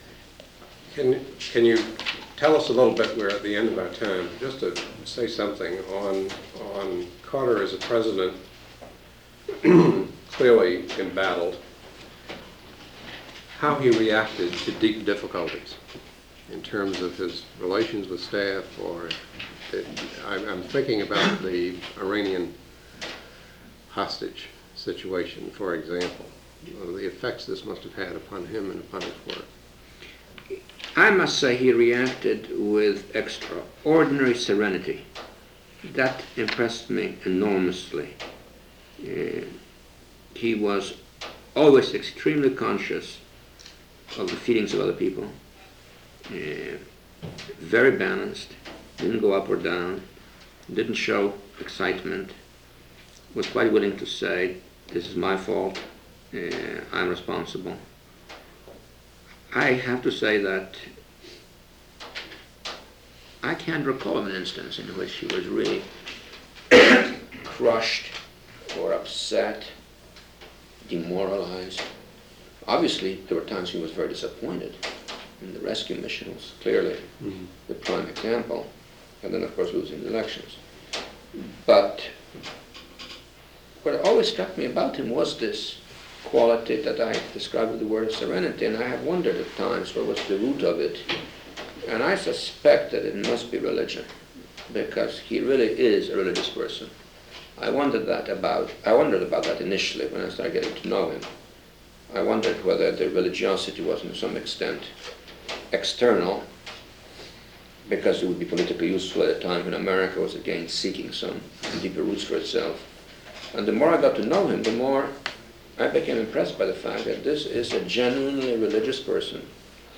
'Carter's Inner Strength' Photo: Jimmy Carter Library, National Archives As assistant to the president for national security affairs, Zbigniew Brzezinski worked closely with President Jimmy Carter and knew him personally. Brzezinski reflected on Carter’s sources of inner strength as a president embattled. Date: February 18, 1982 Participants Zbigniew Brzezinski Associated Resources Zbigniew Brzezinski Oral History The Jimmy Carter Presidential Oral History Audio File Transcript